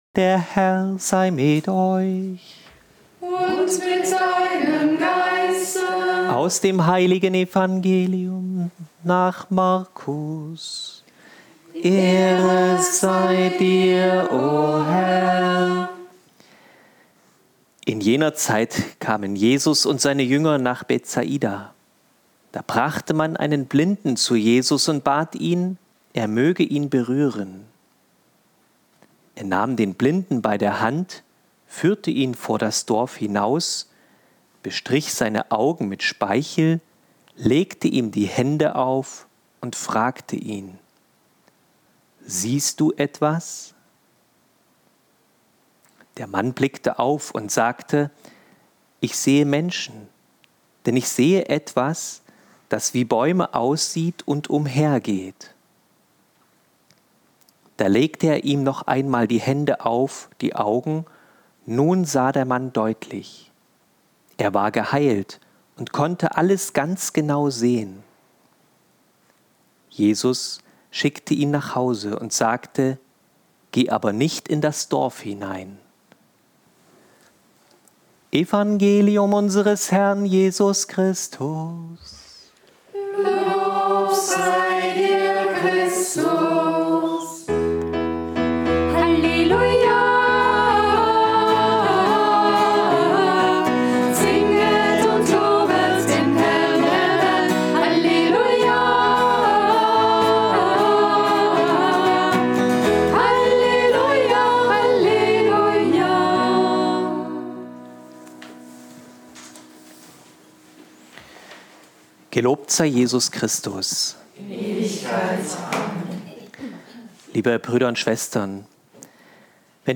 Da der Mariathon, Spendenmarathon an der Hand Mariens, auch immer ein großes Fest für uns ist, gab es um 9 Uhr eine Hl. Messe.